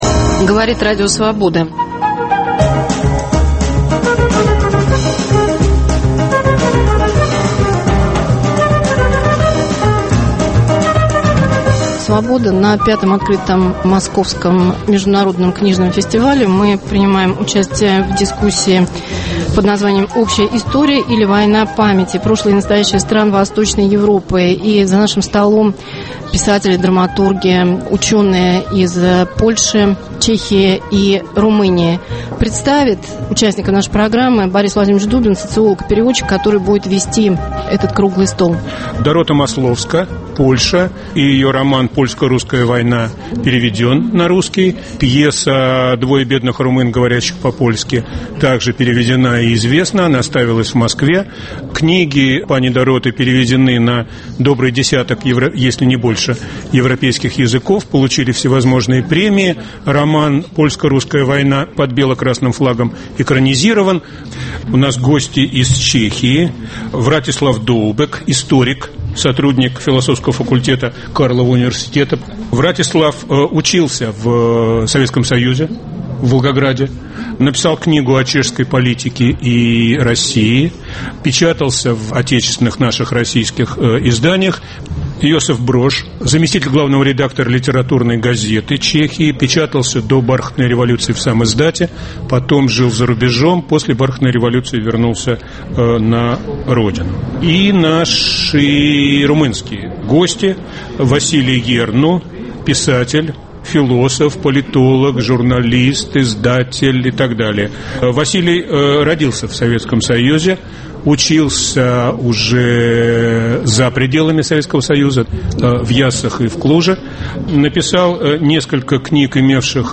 "Свобода в клубах" на Московском книжном фестивале. Общая история или война памяти? Прошлое и настоящее стран Восточной Европы глазами современных писателей. Боятся ли России и думают ли о ней в Польше, Румынии и Чехословакии?